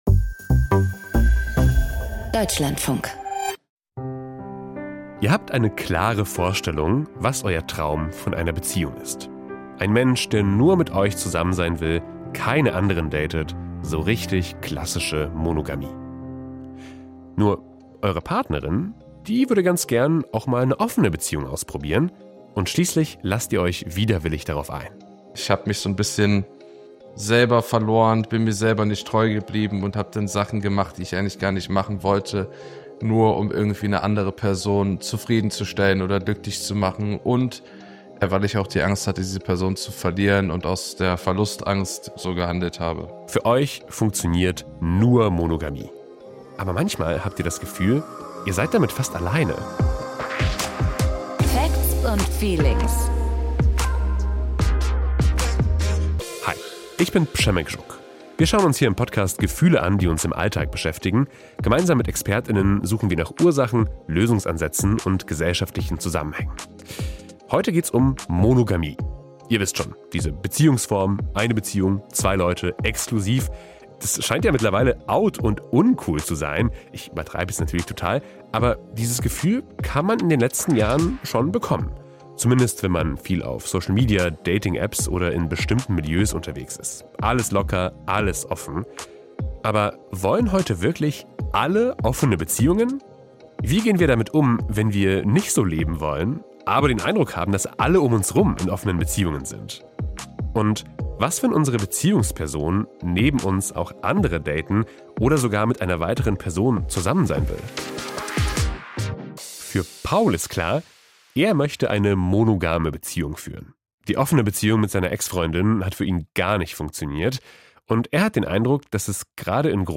Zwei Expertinnen erklären, was wir für uns klären sollten.